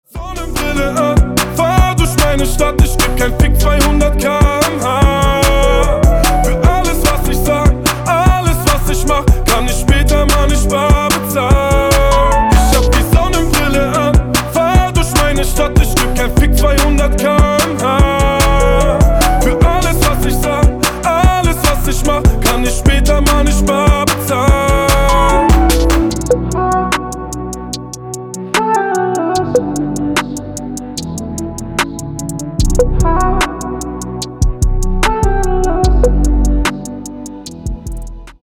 • Качество: 320, Stereo
мужской вокал
Хип-хоп
мелодичные